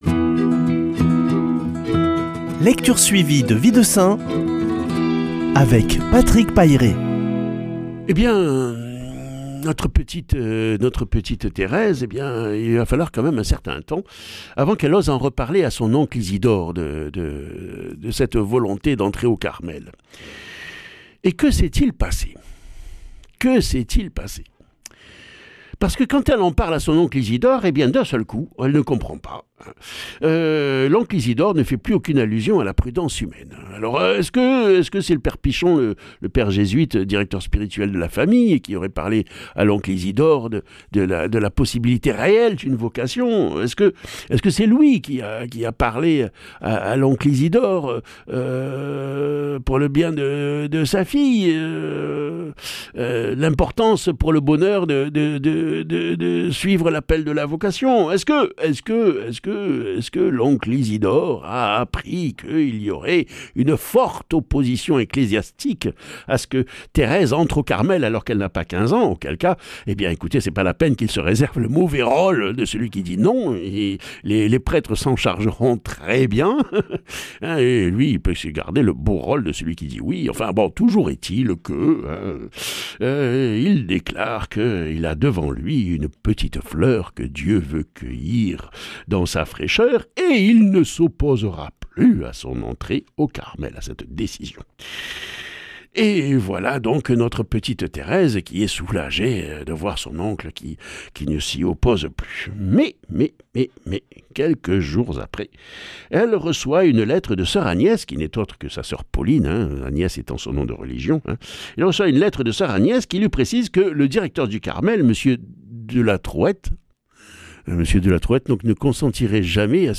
Lecture suivie de la vie des saints